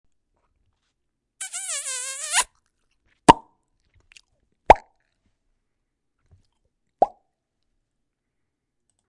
Download Kiss sound effect for free.